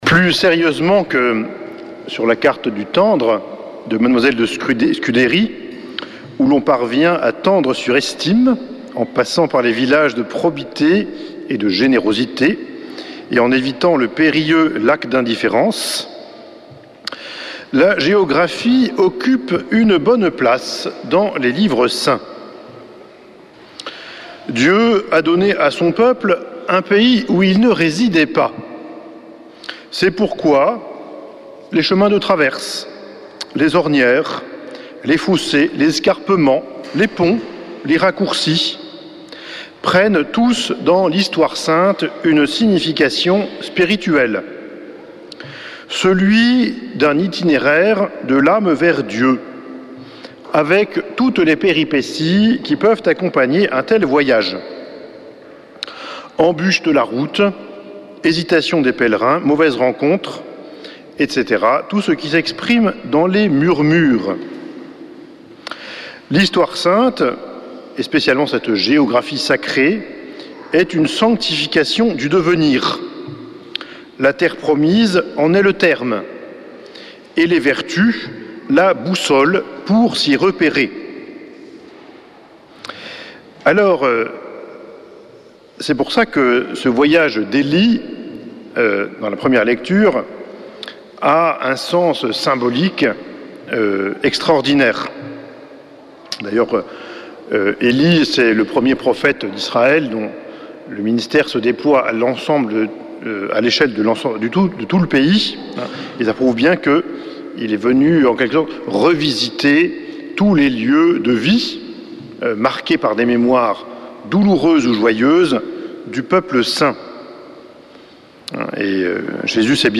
Homélie du 11 août